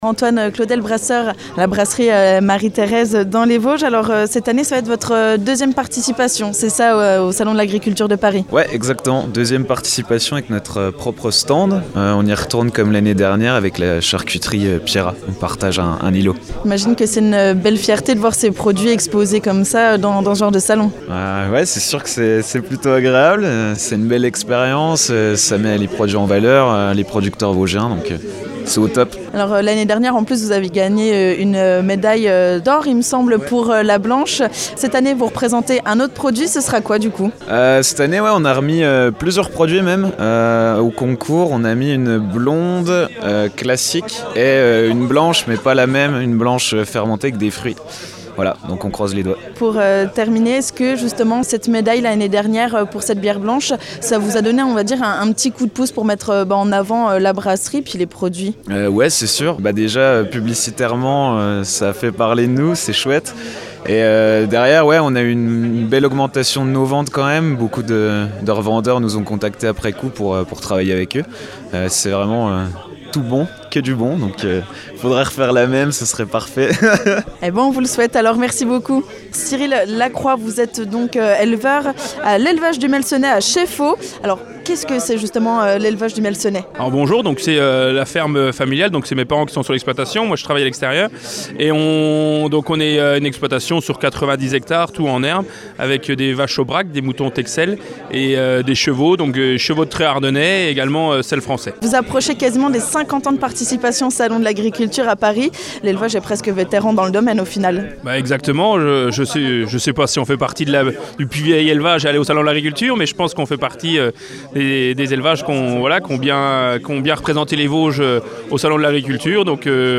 Nous avons tendu notre micro à plusieurs producteurs et éleveurs qui seront présents sur place.